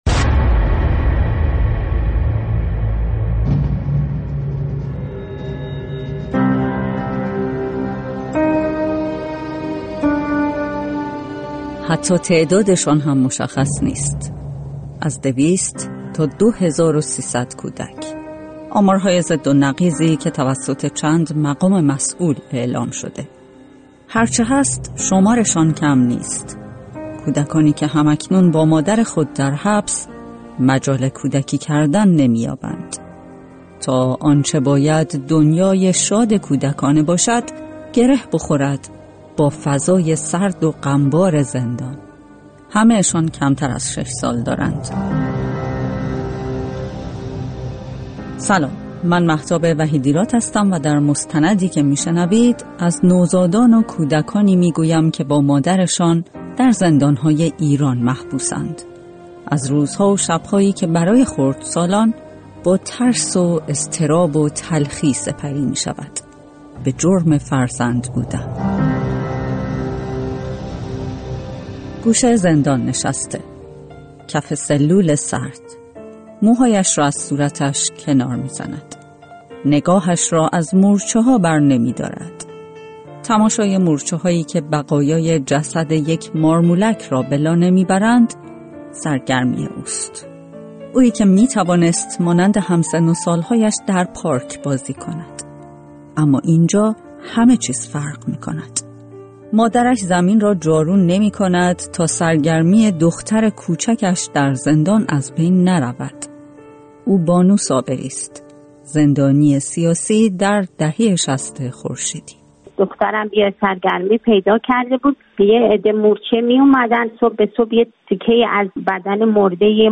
مستند رادیویی: میله‌های کودکی؛ آنچه بر ناخوانده‌های کوچک زندان می‌گذرد